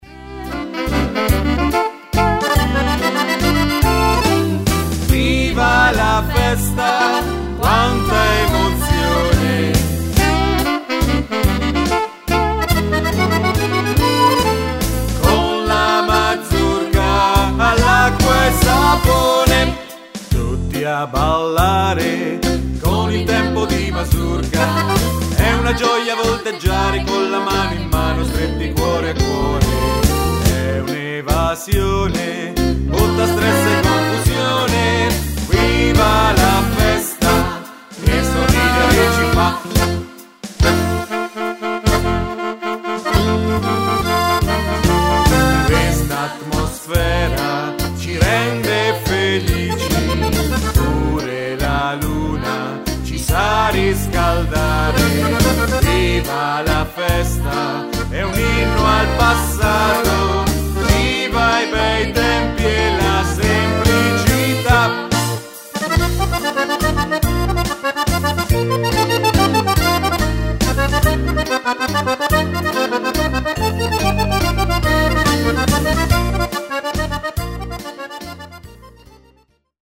Mazurca
Uomo